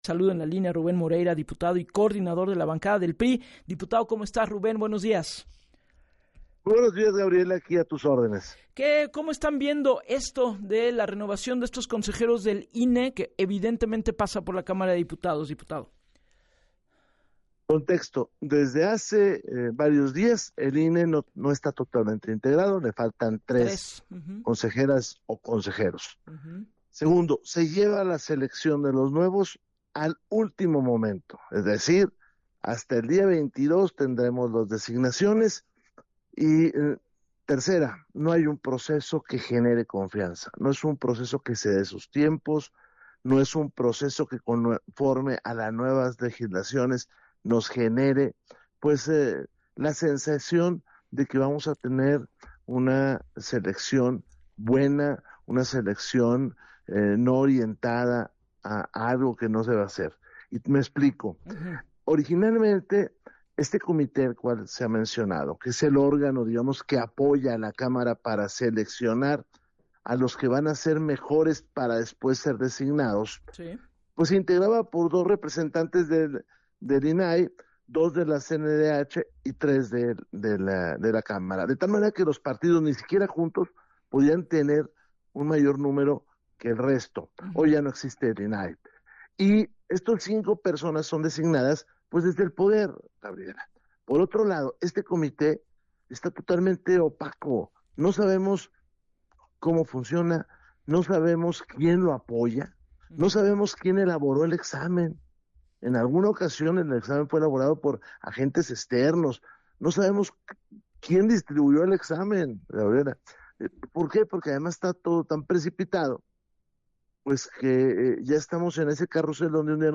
La renovación en el INE no genera confianza, advirtió el líder de la bancada del PRI en la Cámara de Diputados, Rubén Morerira en entrevista con Gabriela Warkentin, para “Así las Cosas”, en donde aseguró que “el INE recibe embates desde el gobierno”.